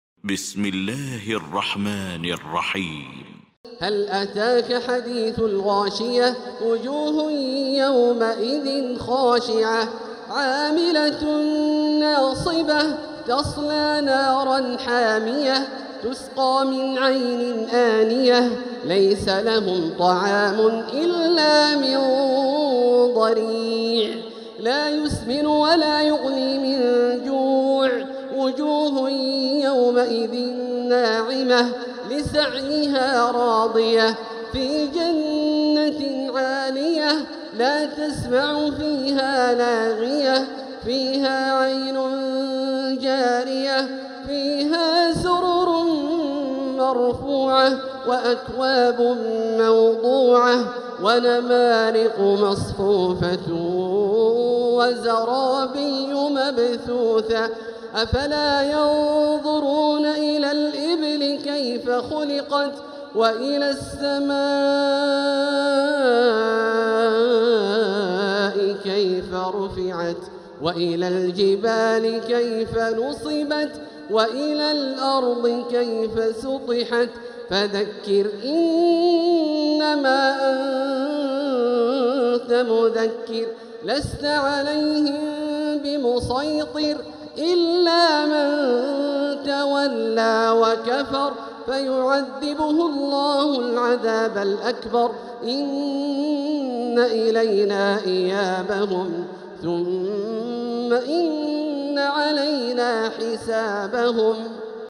سورة الغاشية Surat Al-Ghashiyah > مصحف تراويح الحرم المكي عام 1446هـ > المصحف - تلاوات الحرمين